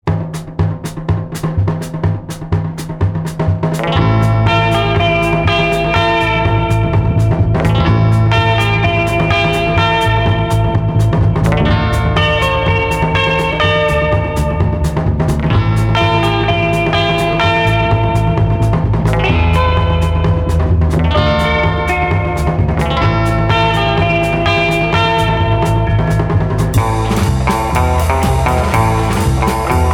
Rock instrumental